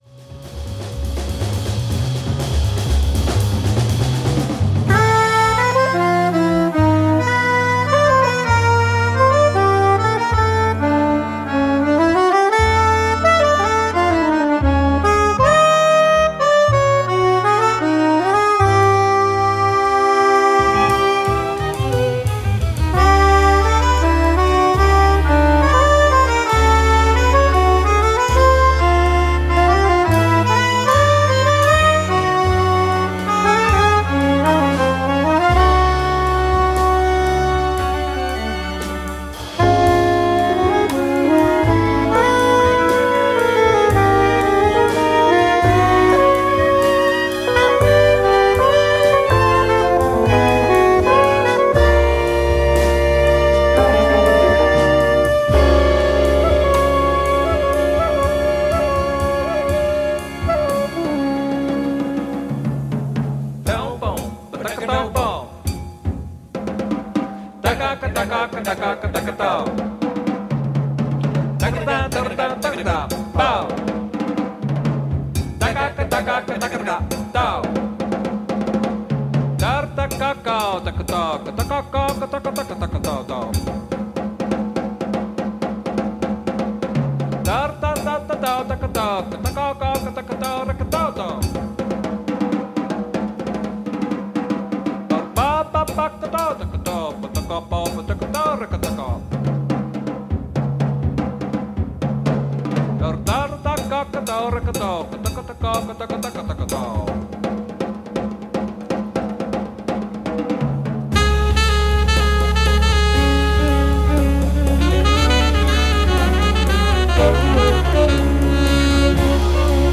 one cooking band
chamber-jazz